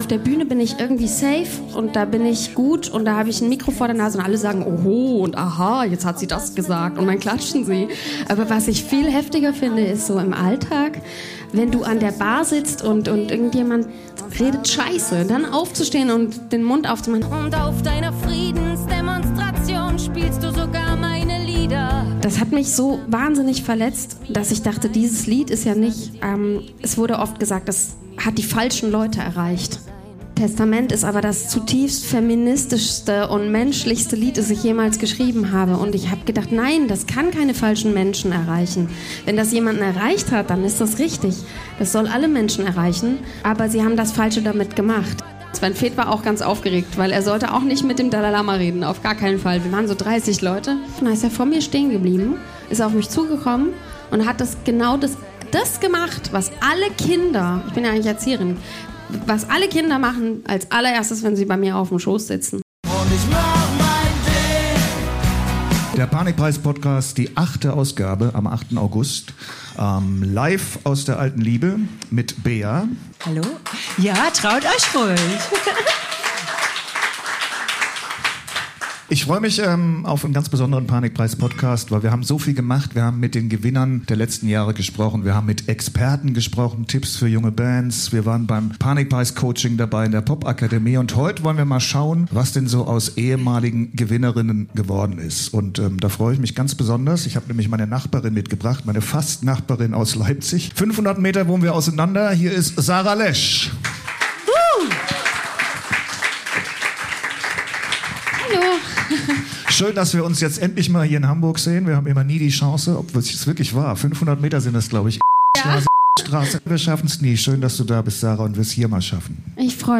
Eine außergewöhnliche Frau ist diesmal bei uns zu Gast.